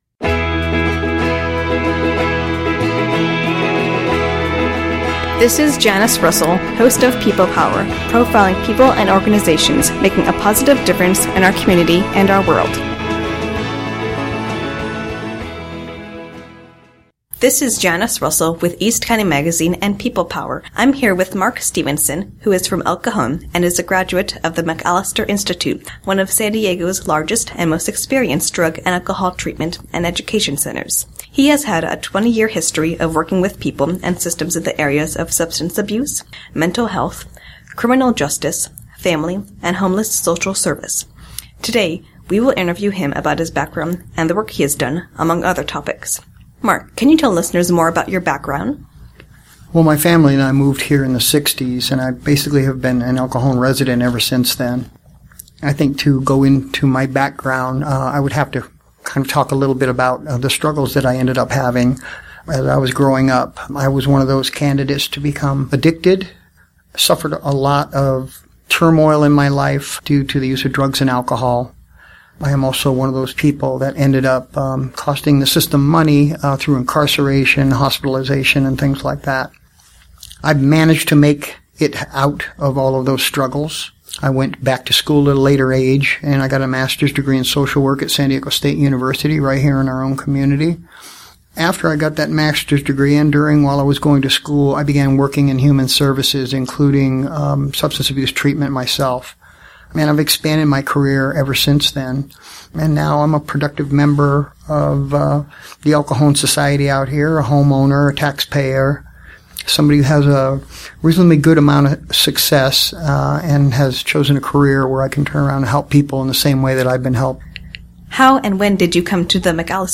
Update: Our interview is now posted.
.The show airs from 5 to 6 p.m. and the interview is in the second half of the hour.